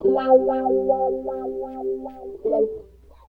70 GTR 4  -R.wav